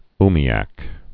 (mē-ăk)